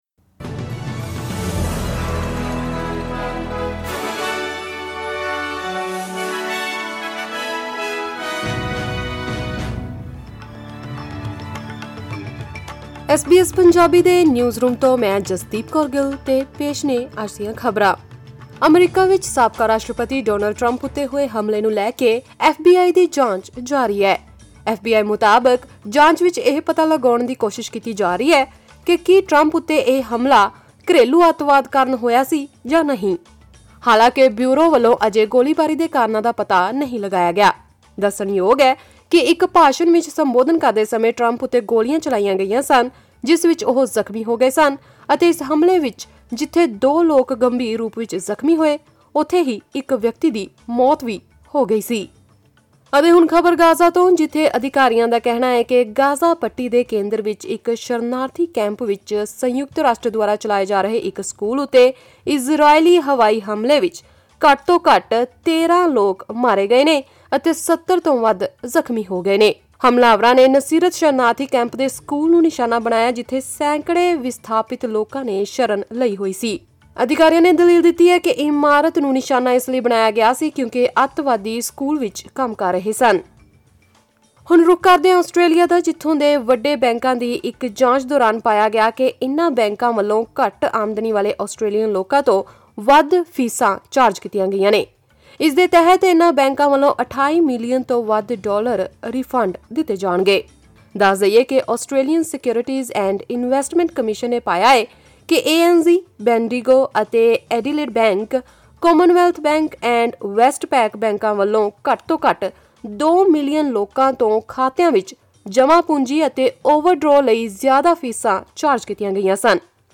ਐਸ ਬੀ ਐਸ ਪੰਜਾਬੀ ਤੋਂ ਆਸਟ੍ਰੇਲੀਆ ਦੀਆਂ ਮੁੱਖ ਖ਼ਬਰਾਂ: 15 ਜੁਲਾਈ 2024